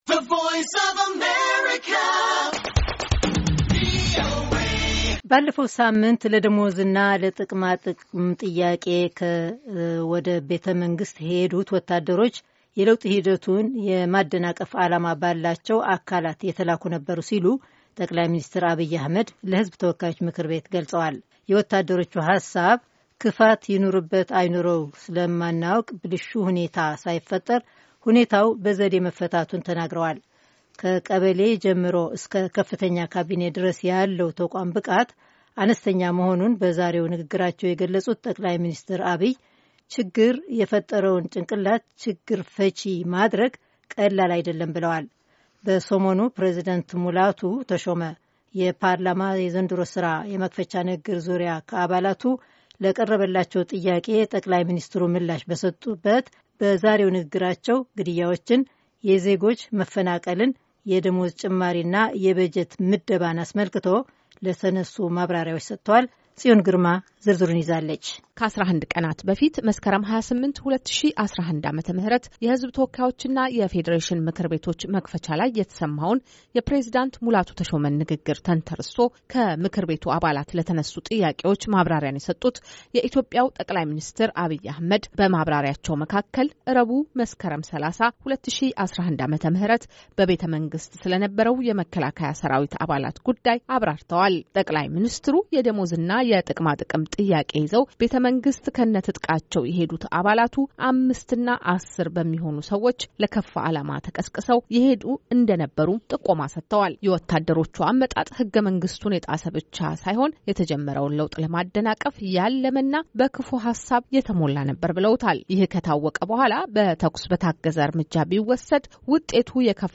የጠቅላይ ሚኒስትር አብይ አሕመድ የሕዝብ ተወካዮች ምክር ቤት ማብራሪያ